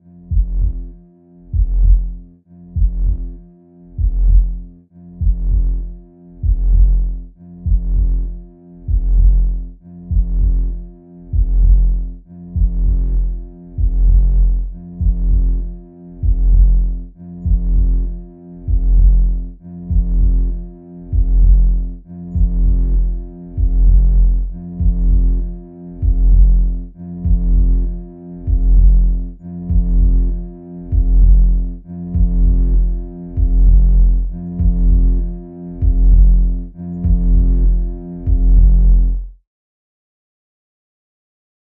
在你的附近 " 低音脉冲
描述：短黑环 使用逻辑 重复低音脉冲 大提琴合成器分层脉冲踢
标签： 循环 环境 悬念 无人驾驶飞机 黑暗 精神恍惚 击败了 低音
声道立体声